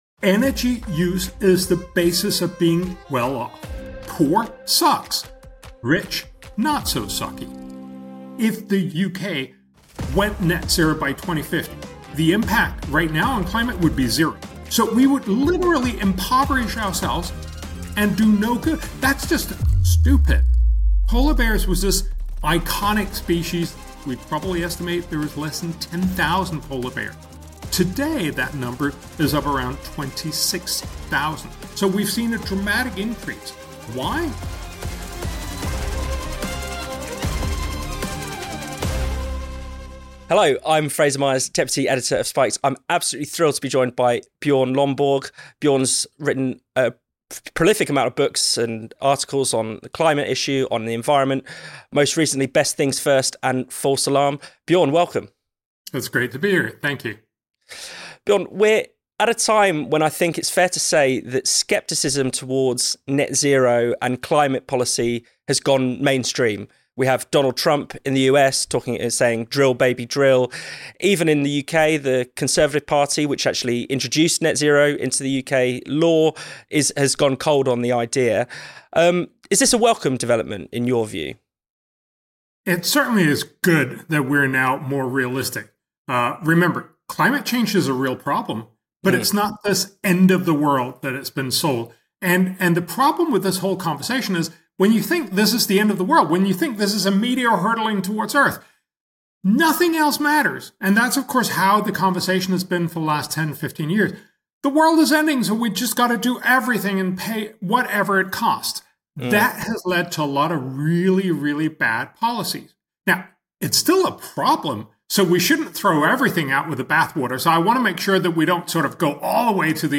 This is the audio from a video we have just published on our YouTube channel – an interview with Bjorn Lomborg.